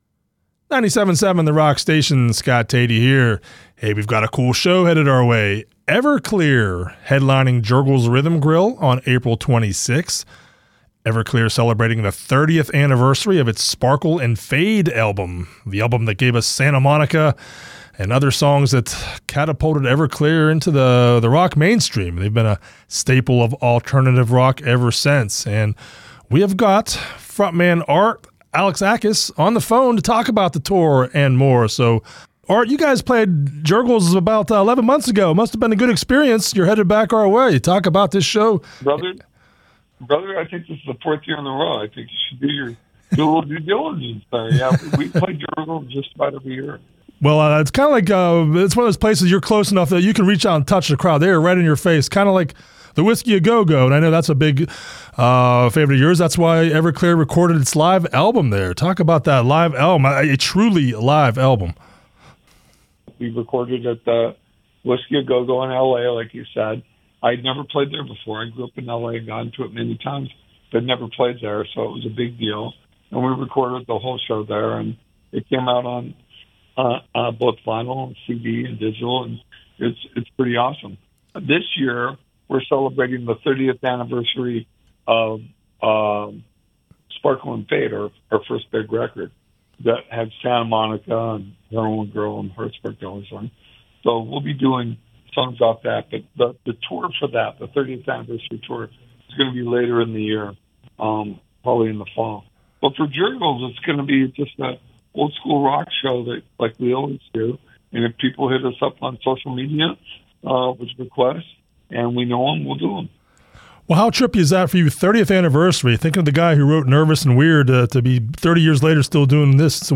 Everclear frontman Art Alexakis talks to The Rock Station about his famed band returning to Jergel’s Rhythm Grille for an April 26 concert.
Everclear-interview.mp3